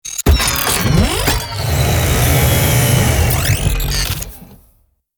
Robot Power Down, Shutdown Sound Effect Download | Gfx Sounds
Robot power down, shutdown Sound Effect Download.
Robot-power-down-shutdown.mp3